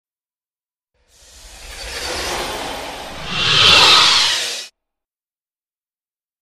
PowerDownFinal.mp3